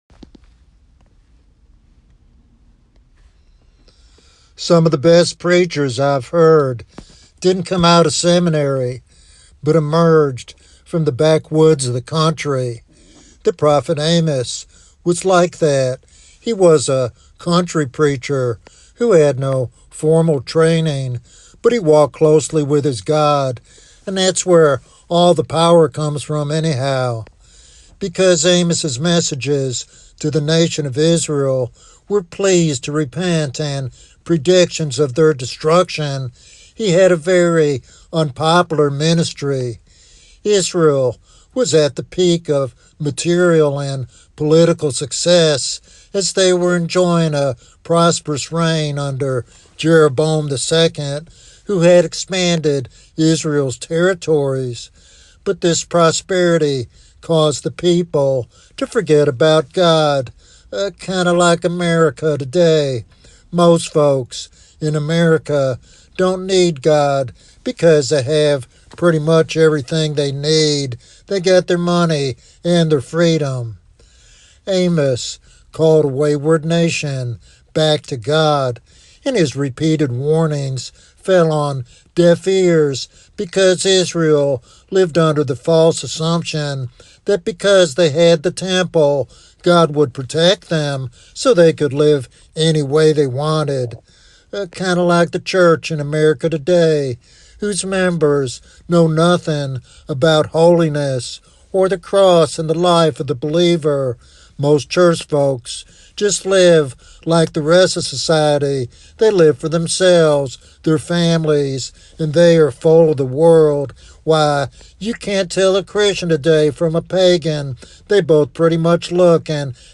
This sermon challenges believers to recognize the seriousness of their spiritual condition and the urgent need for God's intervention.